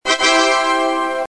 Roblox Victory Sound Effect Free Download